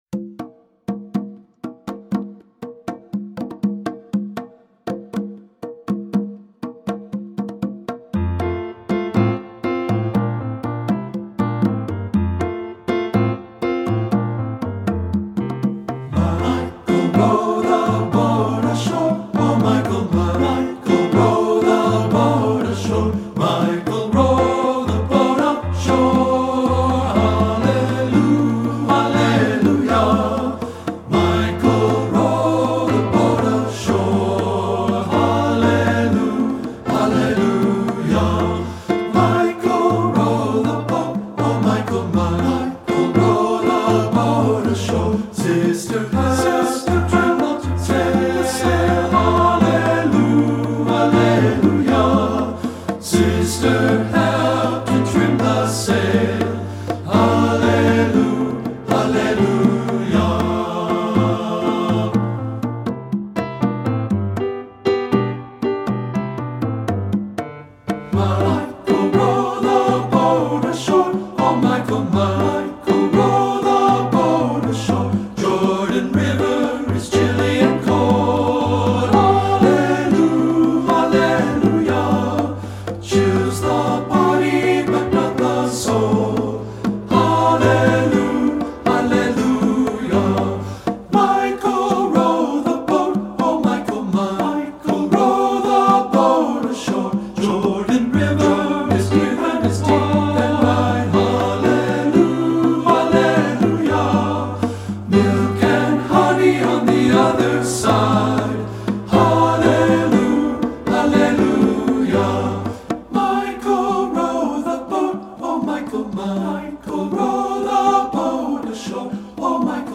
Composer: Traditional Spiritual
Voicing: TB and Piano